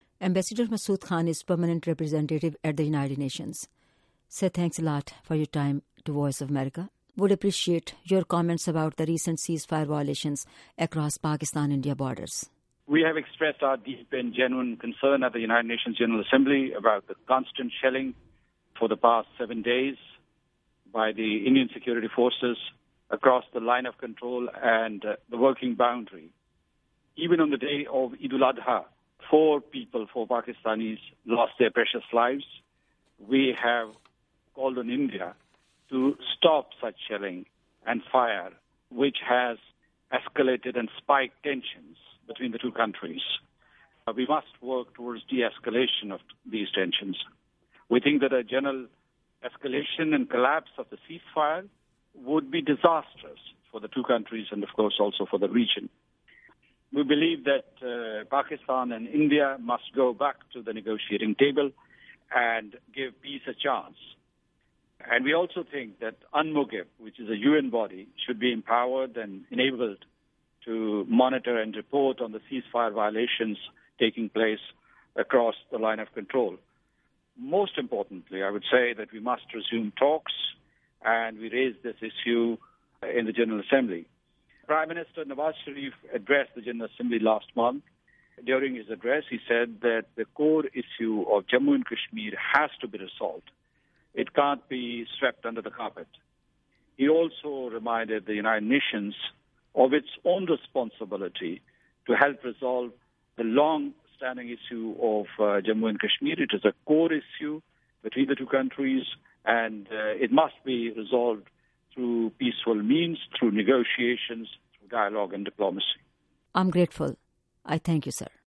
سفیر مسعود خان کا انٹرویو